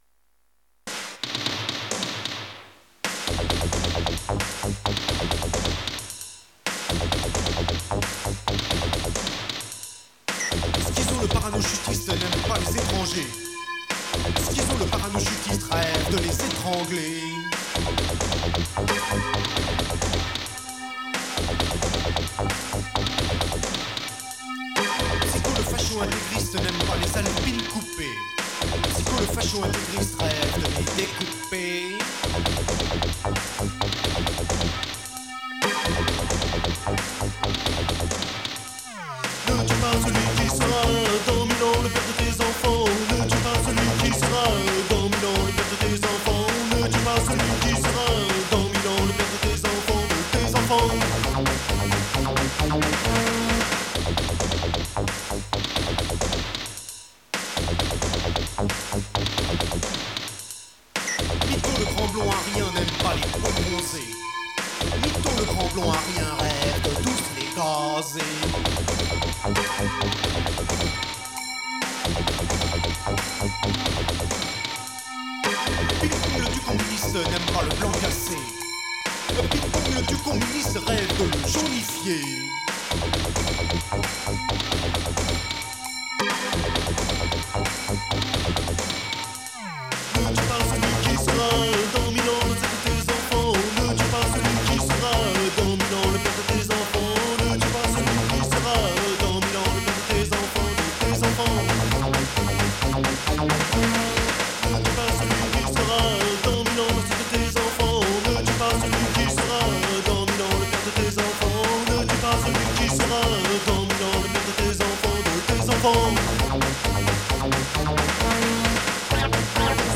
De nouveaux synthés
Au final un mixage très soufflant, mais des chansons que j’aime encore aujourd’hui.